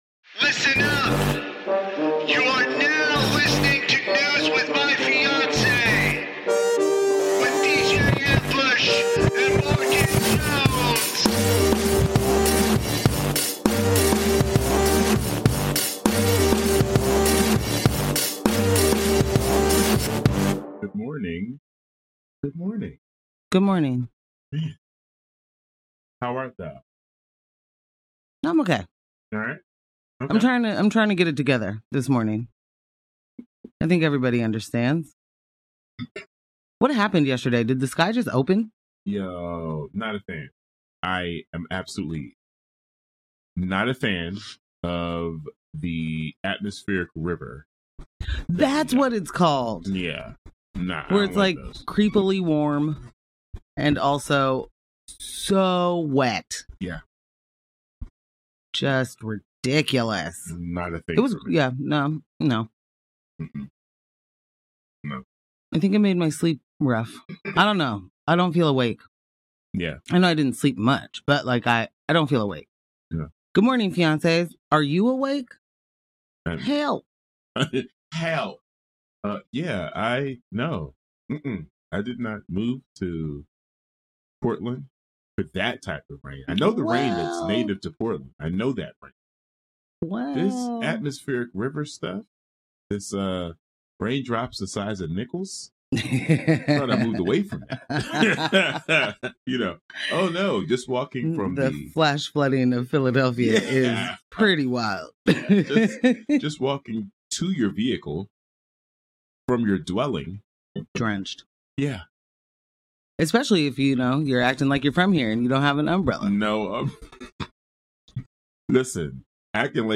*law and order sound*